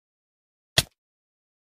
Minecraft-hit-sound---1080p60fps.mp3